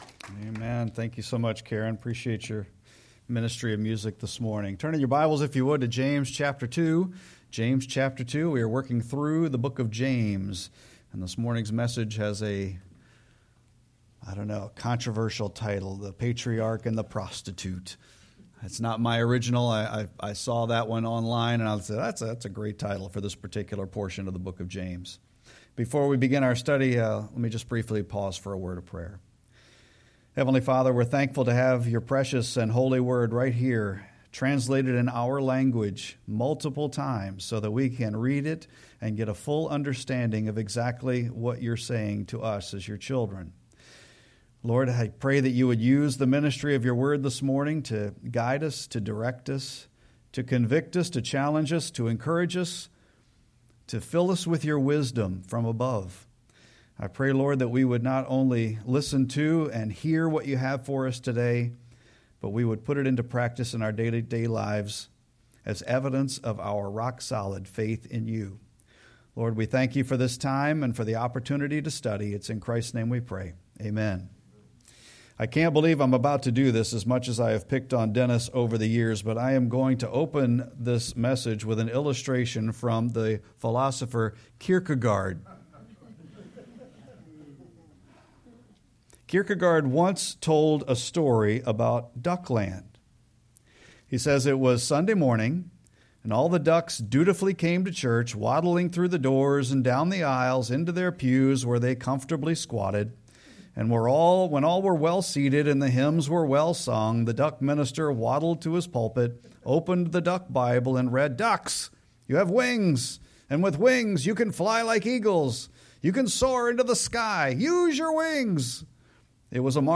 Sermon-7-20-25.mp3